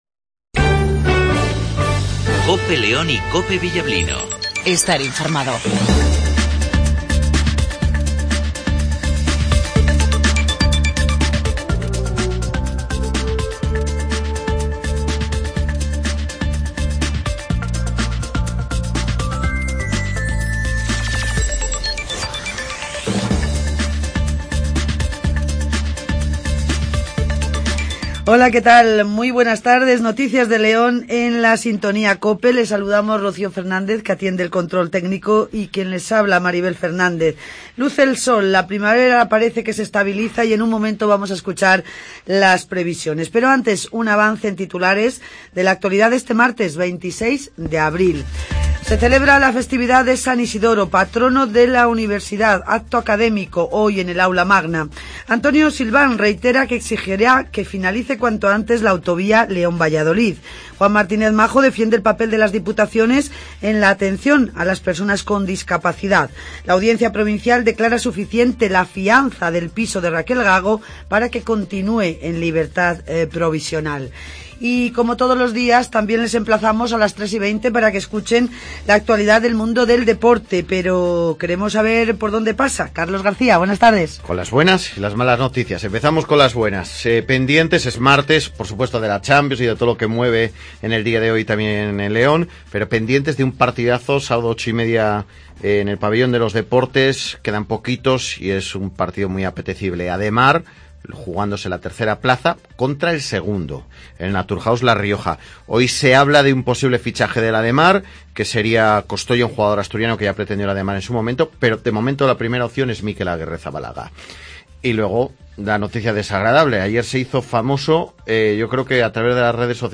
INFORMATIVO MEDIODIA
antonio silván ( Alcalde de León )